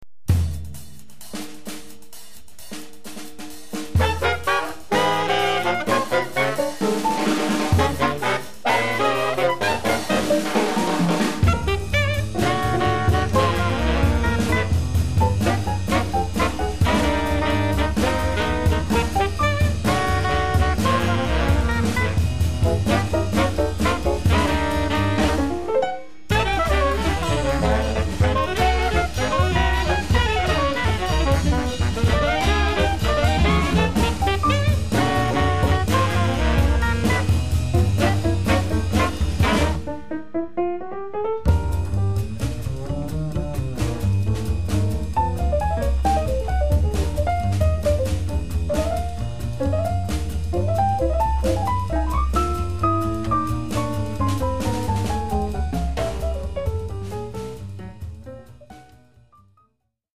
soprano saxophone
alto saxophone
tenor saxophone
baritone saxophone
piano
bass
drums